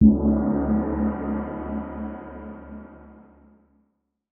Gong (3).wav